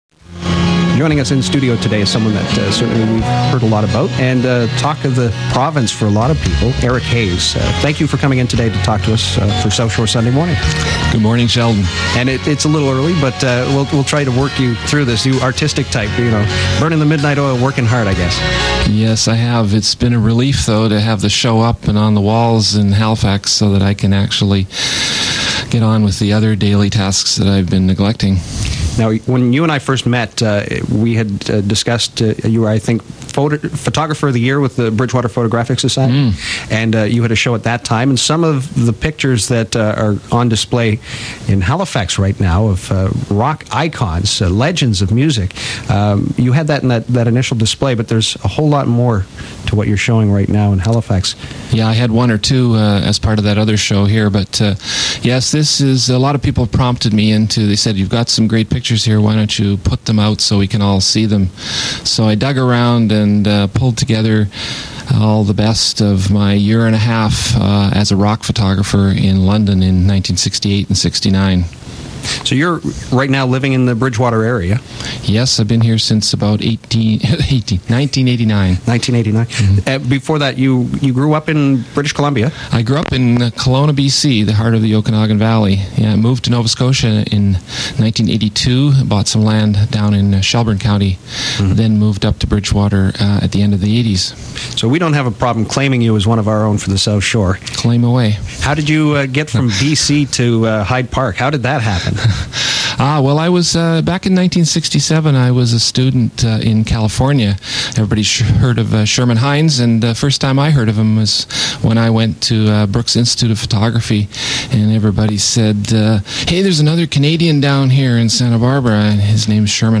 CKBW Interview